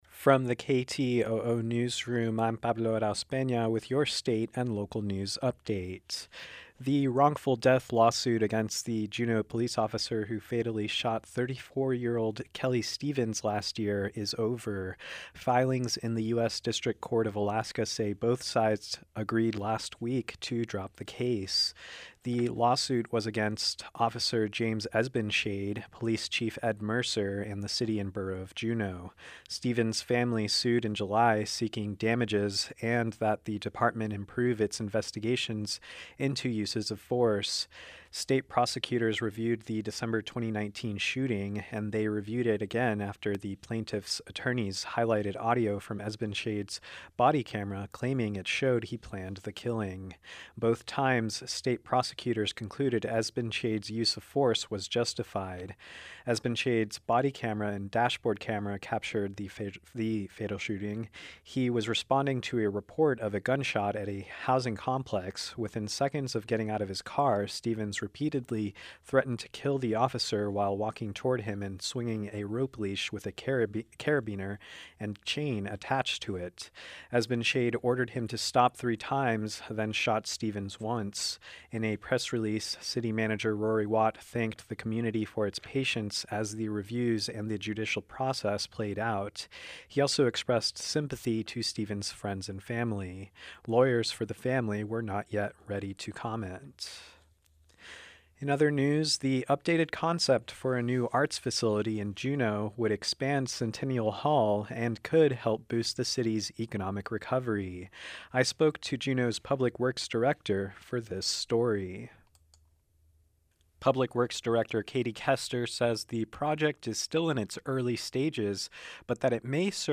Newscast – Thursday, Dec. 10, 2020